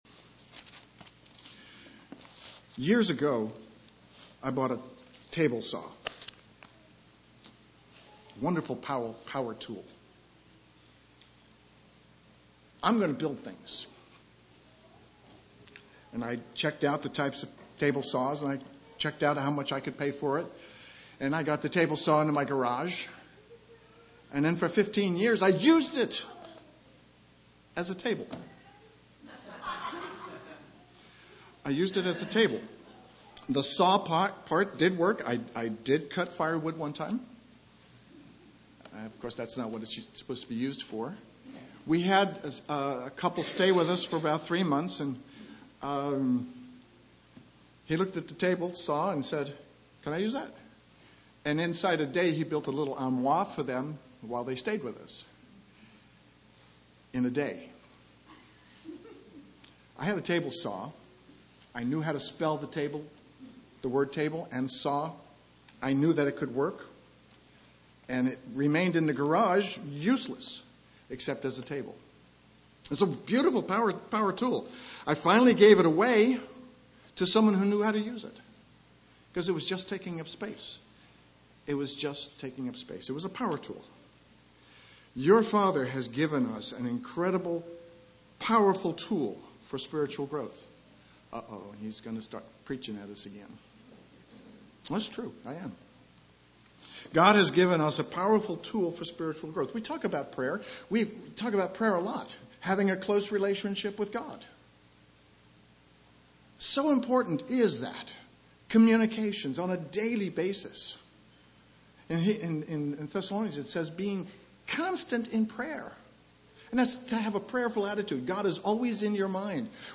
Sermons
Given in Eureka, CA San Jose, CA